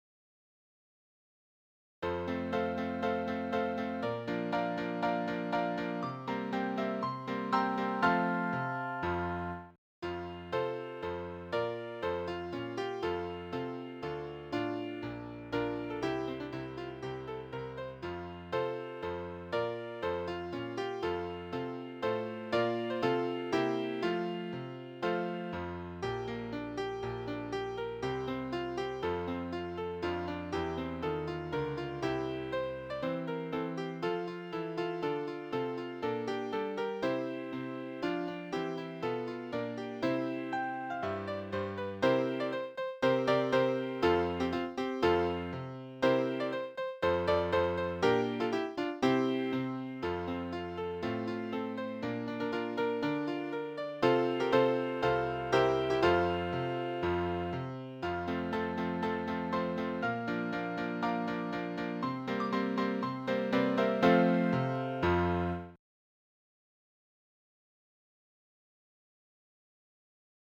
※ピアノ伴奏（前奏－１番－後奏のみ）をお聞きになりたい方は、以下のリンクより再生してください
kouka1-piano.mp3